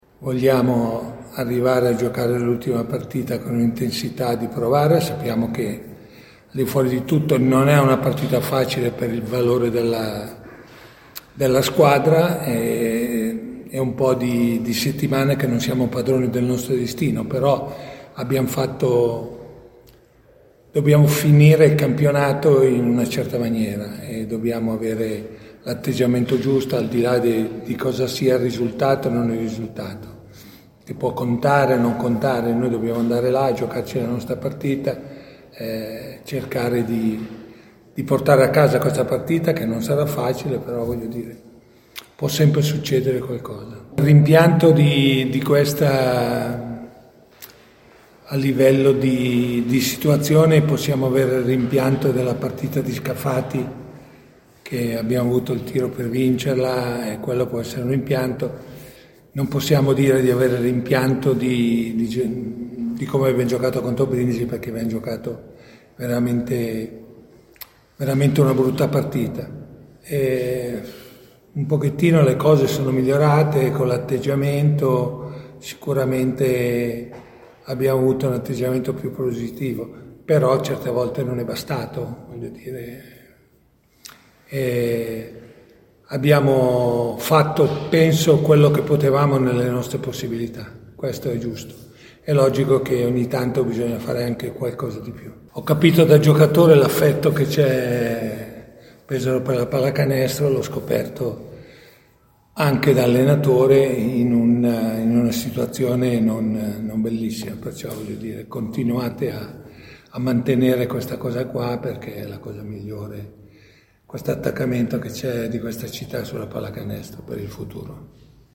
Il Coach della Carpegna Prosciutto Basket Pesaro Meo Sacchetti ha presentato, ai nostri microfoni, il match della 30^ e ultima giornata di Serie A che vedrà i biancorossi impegnati sul parquet del Taliercio contro la Umana Reyer Venezia: palla a due alle 18:15 di domenica 5 maggio.